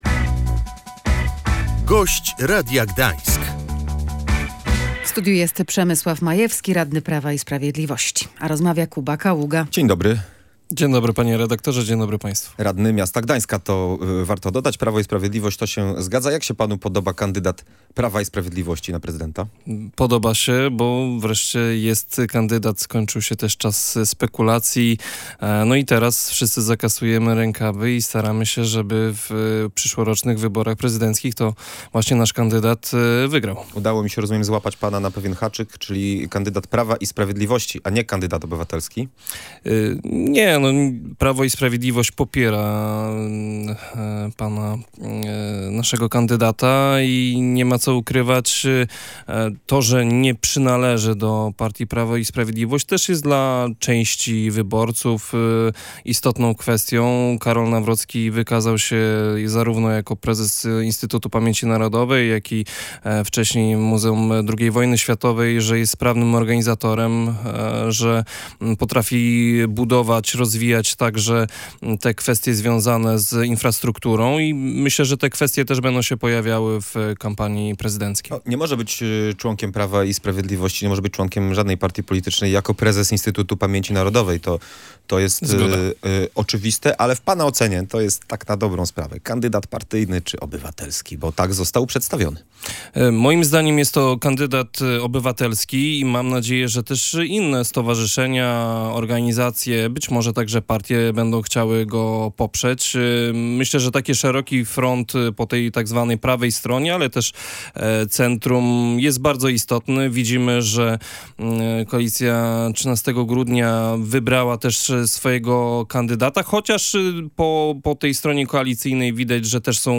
Karol Nawrocki jest kandydatem obywatelskim. Mamy nadzieję, że poprą go też inne organizacje – mówił na naszej antenie Przemysław Majewski, gdański radny Prawa i Sprawiedliwości. Jego zdaniem to będą bardzo istotne wybory dla Polski pod wieloma względami.